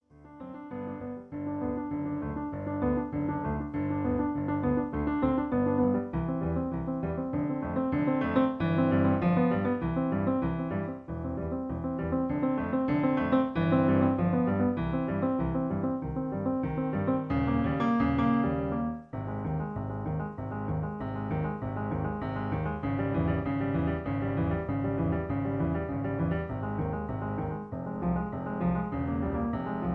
In C. Piano Accompaniment